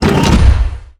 footstep3.wav